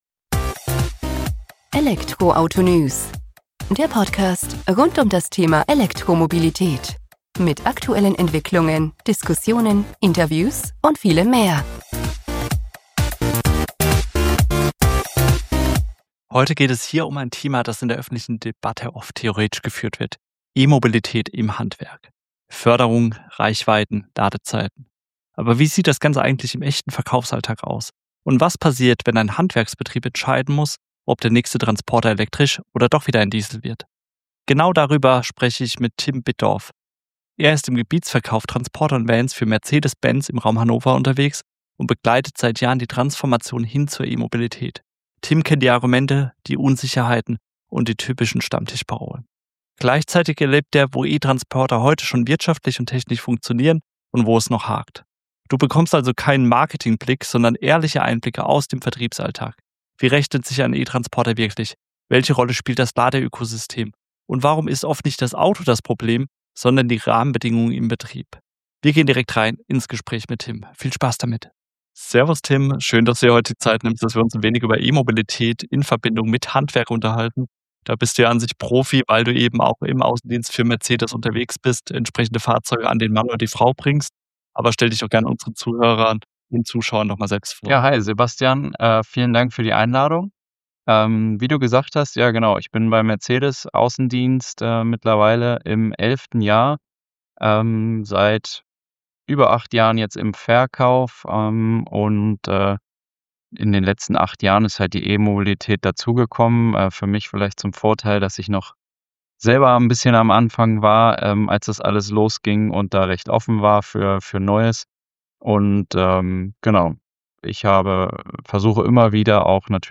Ein zentrales Ergebnis des Gesprächs: Die Alltagstauglichkeit moderner E-Transporter ist in vielen Fällen längst gegeben.